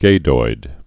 (gādoid, gădoid)